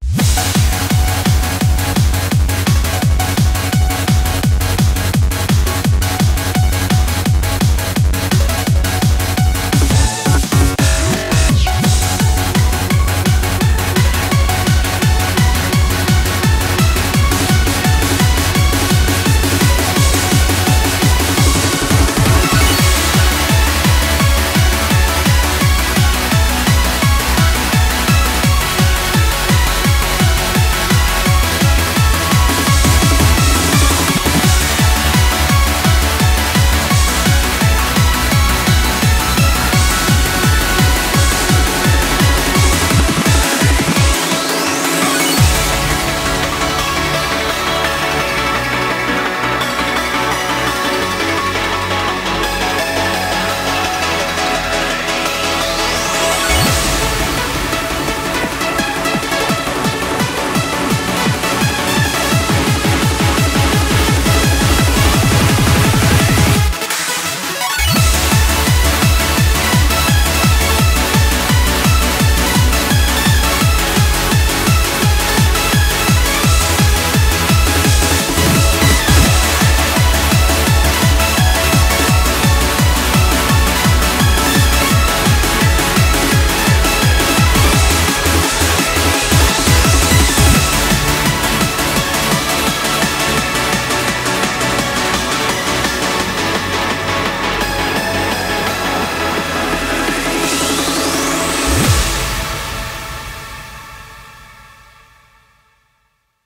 BPM170
Comments[UPLIFTING HARDCORE]